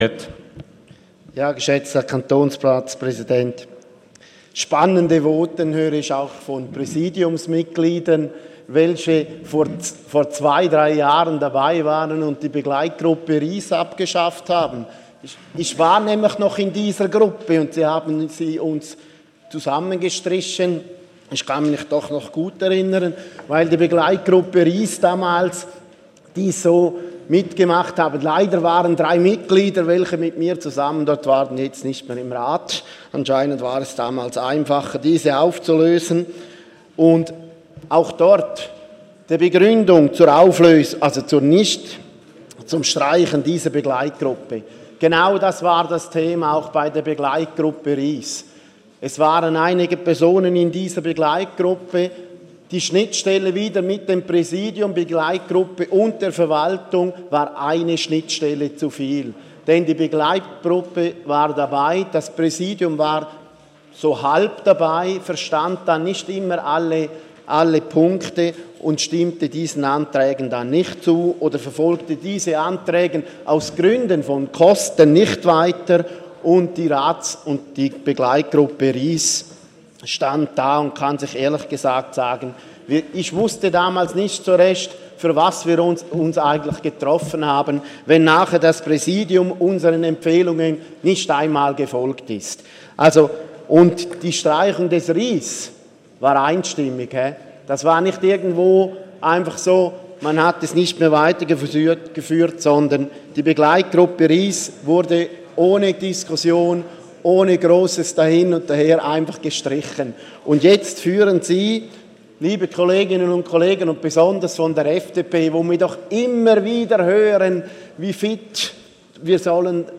Session des Kantonsrates vom 12. und 13. Juni 2017
(im Namen der SVP-Fraktion): Dem Antrag der SVP-Fraktion ist zuzustimmen.